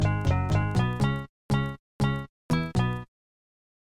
放克吉他弹奏的旋律120 BPM
标签： 120 bpm Hip Hop Loops Guitar Electric Loops 689.24 KB wav Key : Unknown
声道立体声